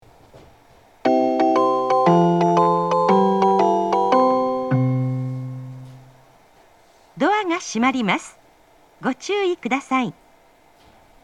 発車メロディー
こちらもスイッチを一度扱えばフルコーラス鳴ります。